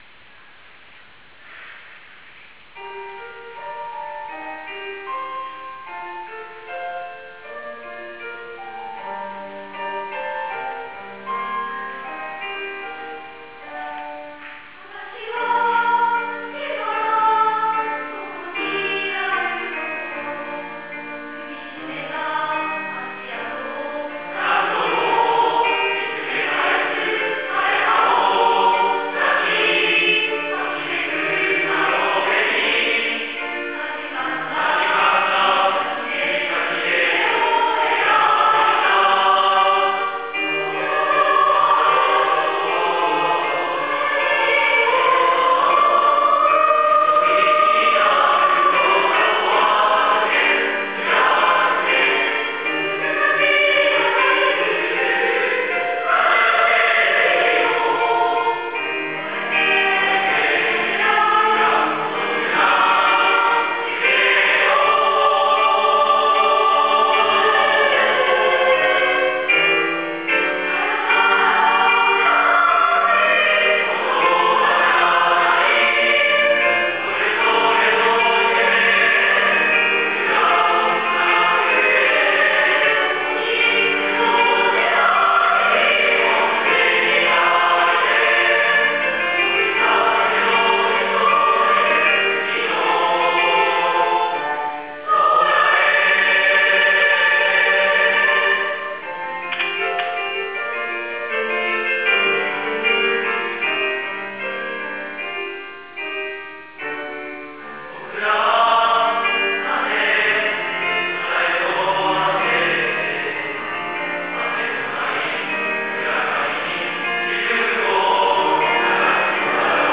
離任式＜３月29日（金）＞
また、部活動等で登校していた生徒に加え、卒業生が花道を作り、お見送りすることができました。